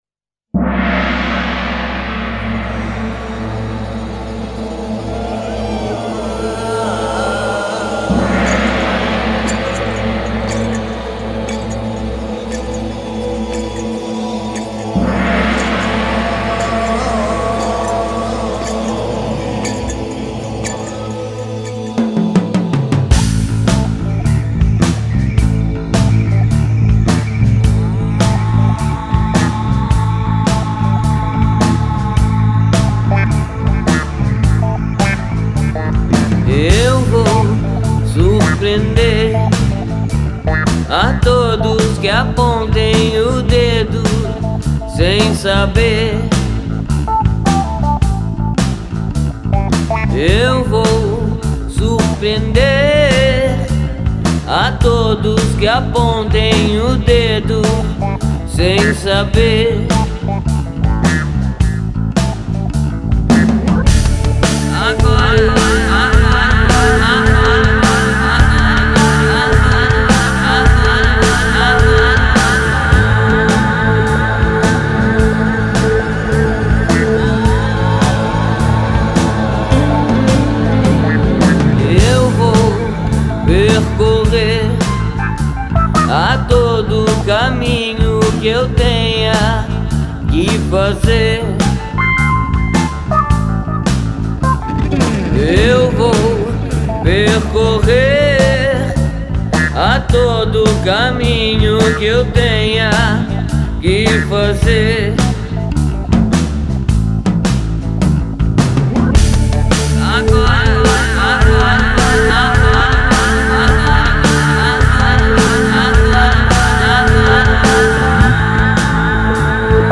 o rock...